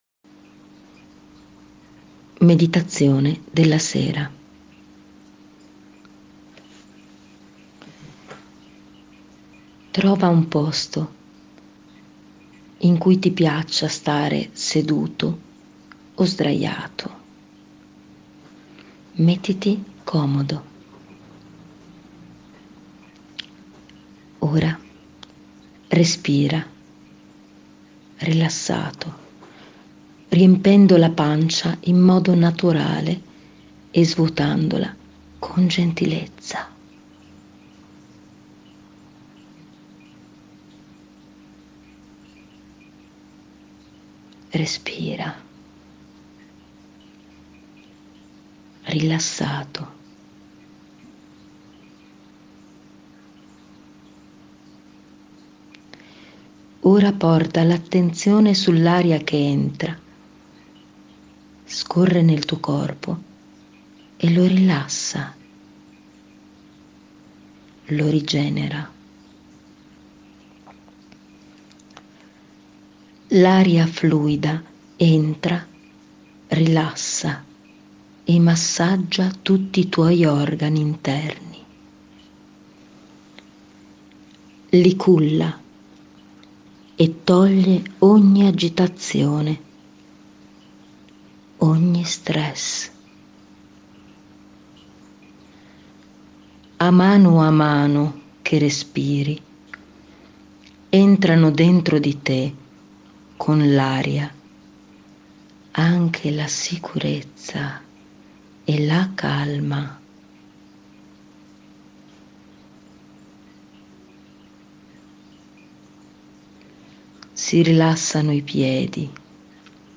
Categoria: Meditazioni